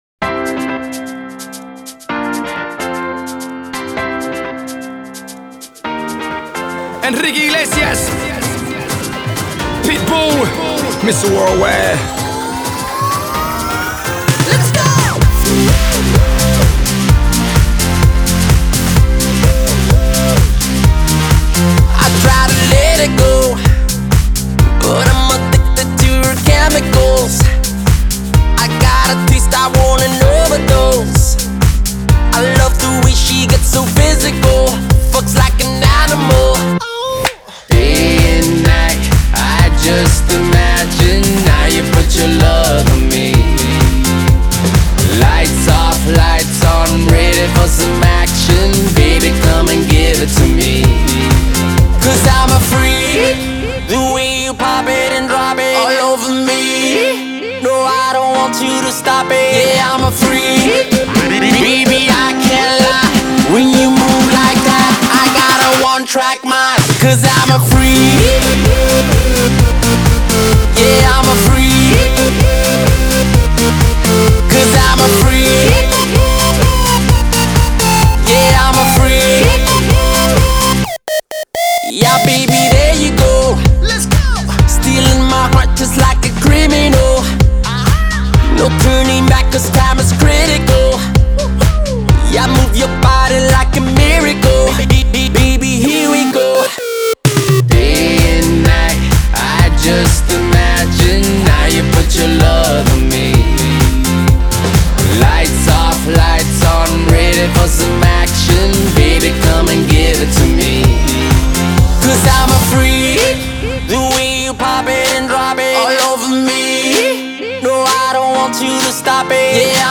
Жанр: Pop, R&B